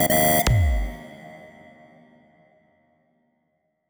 ALERT_Error.wav